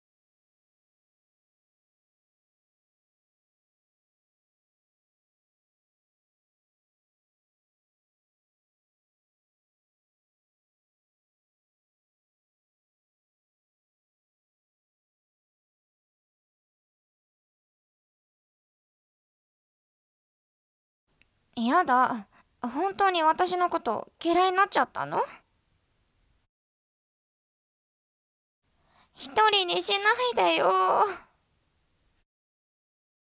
セリフ